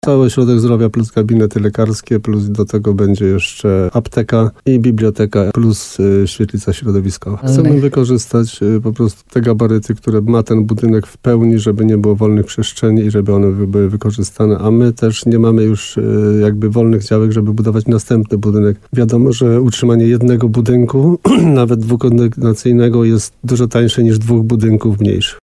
– Inwestycja jest duża – mówi wójt Rytra.